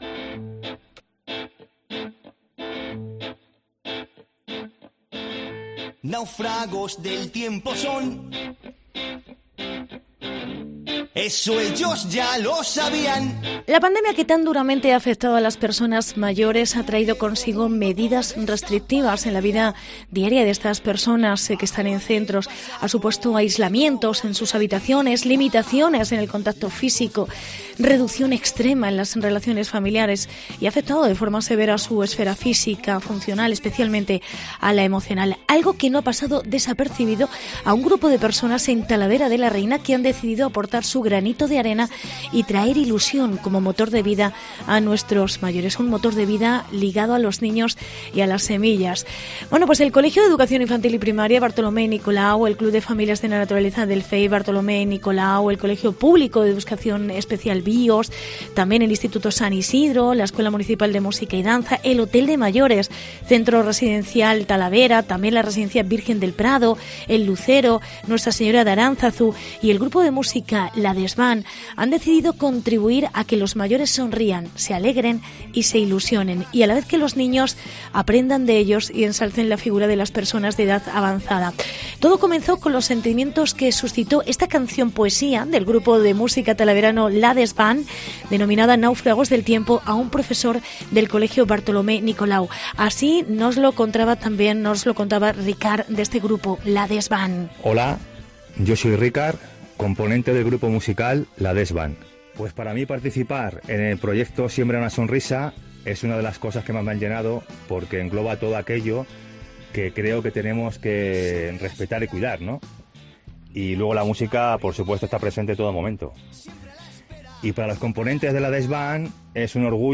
Reportaje "Siembra una sonrisa"